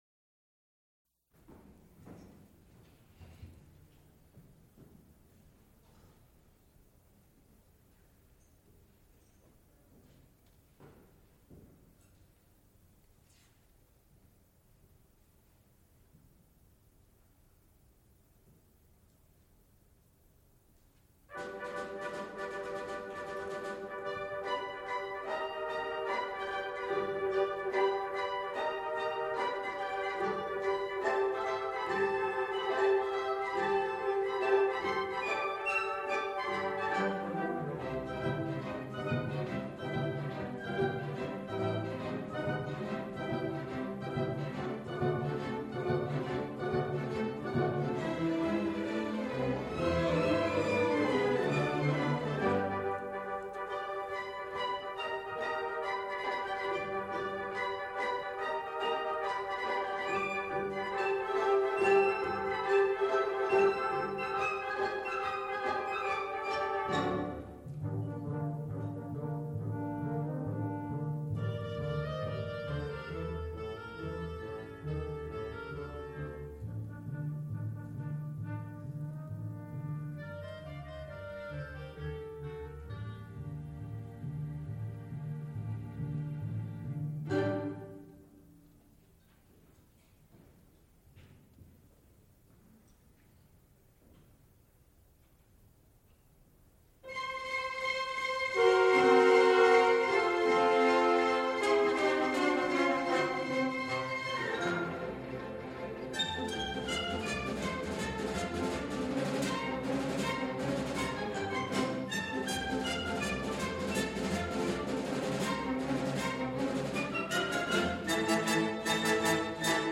Extent 3 audiotape reels : analog, quarter track, 7 1/2 ips ; 7 in.
musical performances
Suites (Orchestra) Songs (High voice) with electronics Songs (High voice) with orchestra